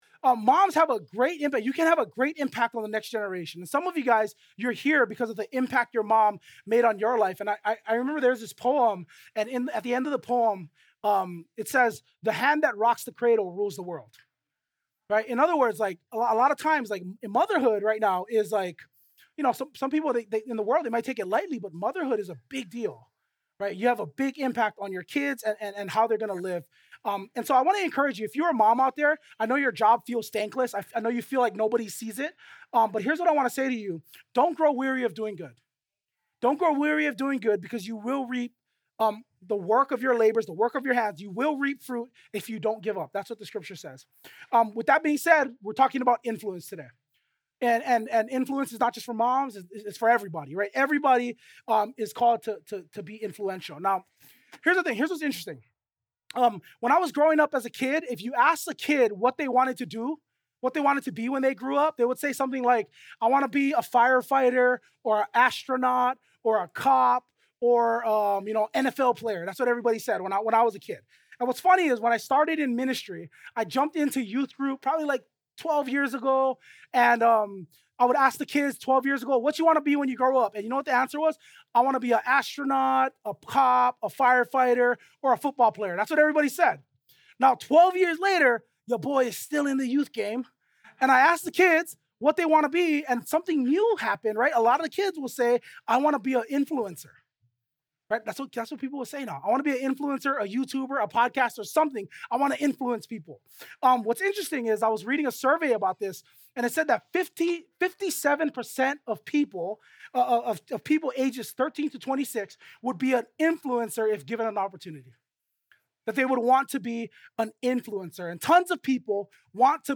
2025 Influence Transformed Preacher